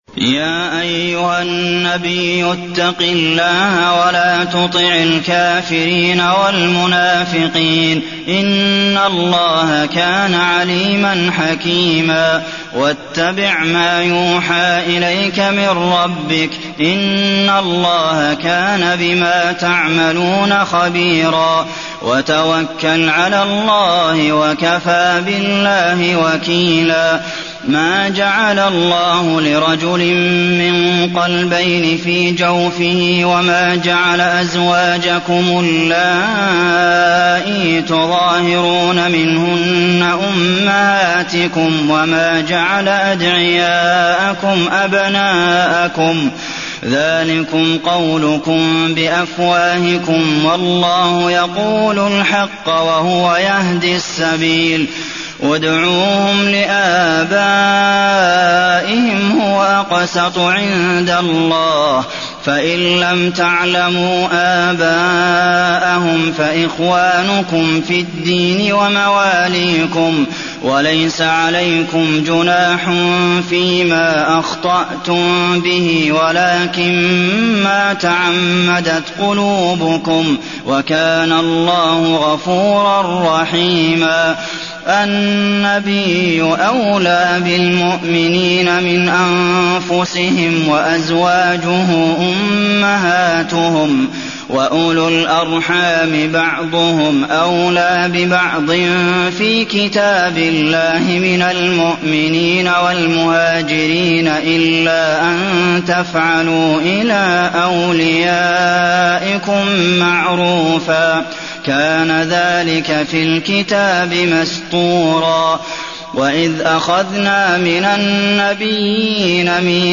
المكان: المسجد النبوي الأحزاب